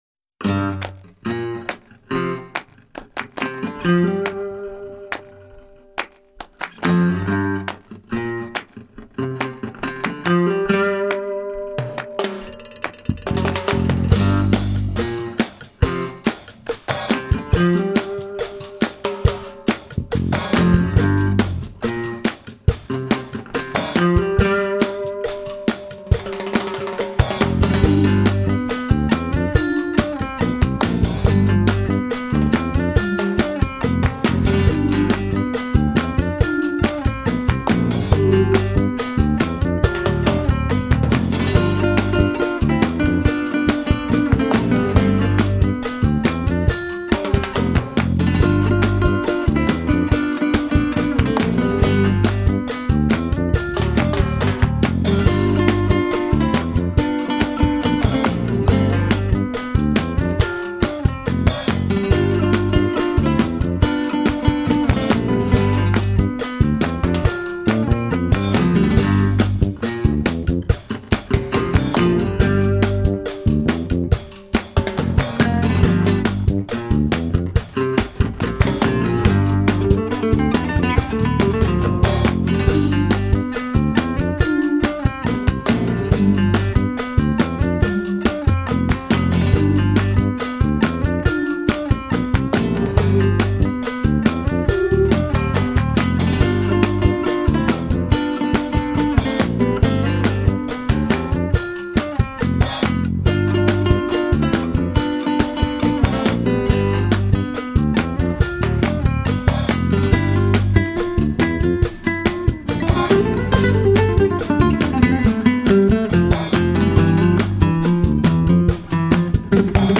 类型:Flamenco